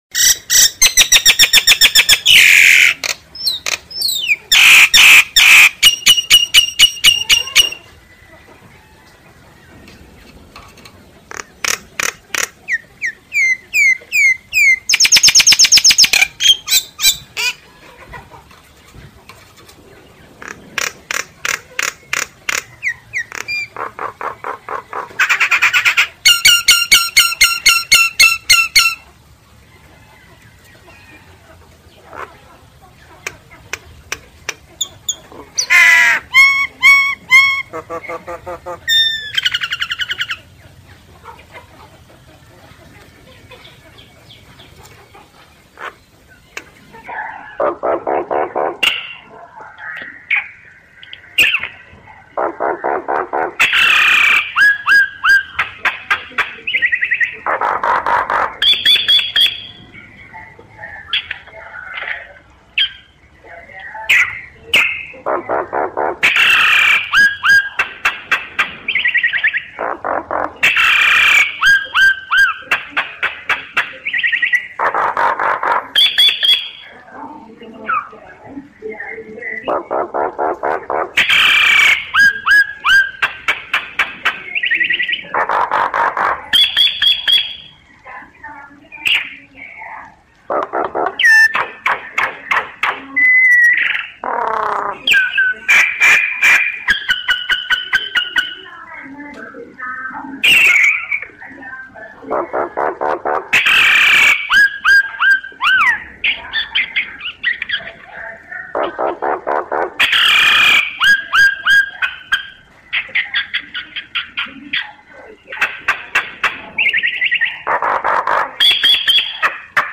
Download tiếng Sáo Nâu hót mp3 chất lượng cao, không có tạp âm, âm thanh to và rõ ràng.
Tiếng Sáo Nâu hót
Chủ đề: tiếng chim cảnh tiếng chim Sáo
tieng-sao-nau-hot-www_tiengdong_com.mp3